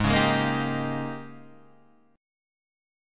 G#M7sus2sus4 chord